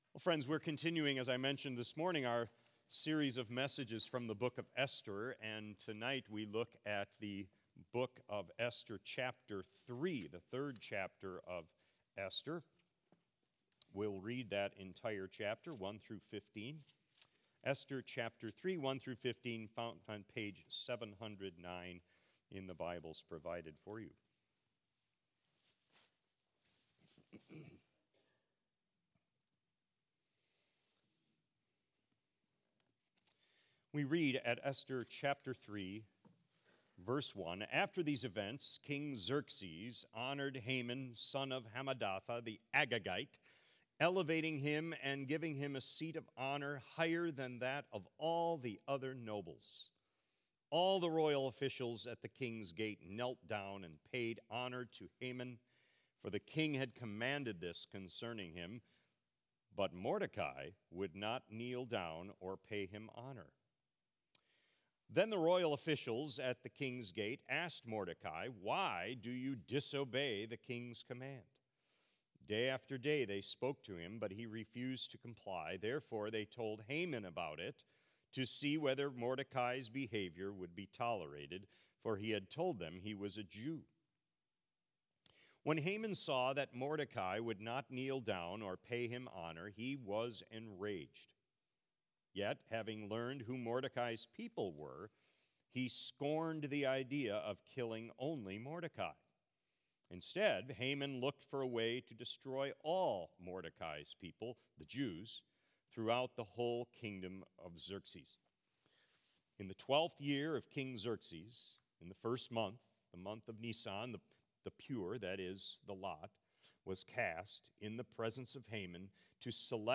Sermons | Faith Community Christian Reformed Church